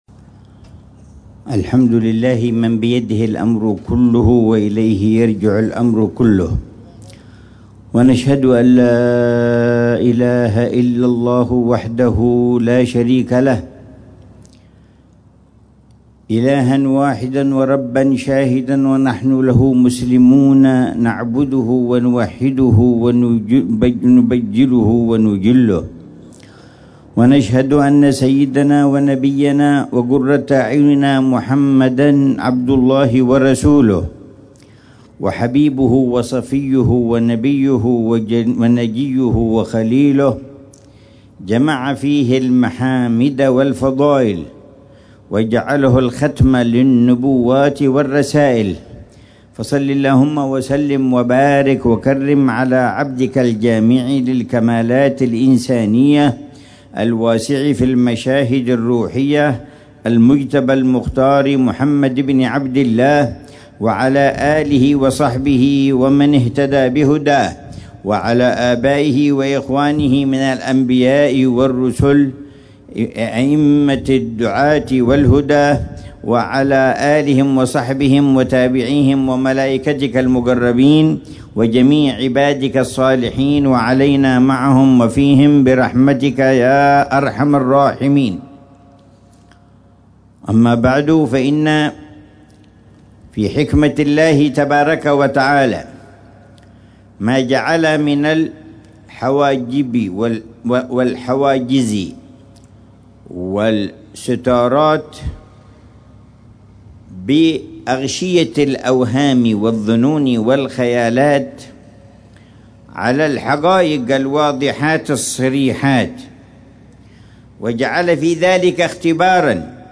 محاضرة العلامة الحبيب عمر بن محمد بن حفيظ في جلسة الجمعة الشهرية الـ64، في ساحة مسجد الزهرة، بحارة النويدرة، بمدينة تريم، ليلة السبت 12 ربيع الثاني 1447هـ، بعنوان: